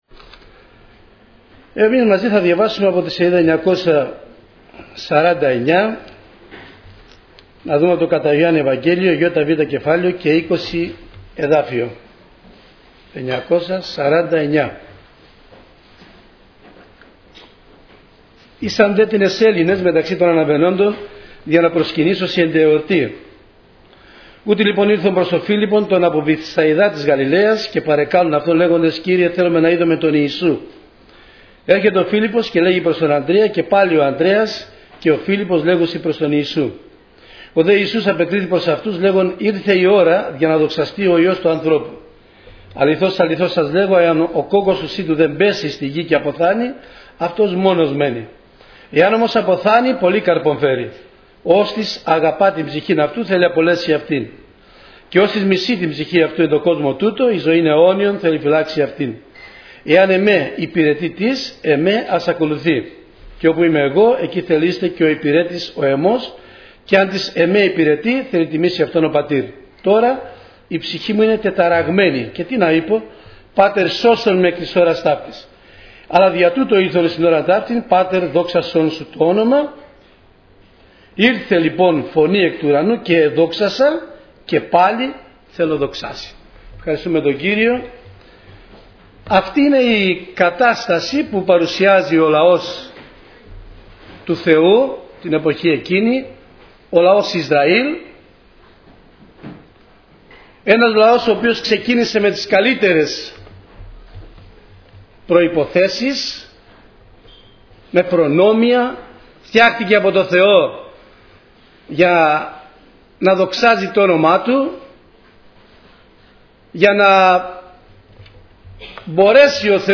Διάφοροι Ομιλητές Λεπτομέρειες Σειρά: Κηρύγματα Ημερομηνία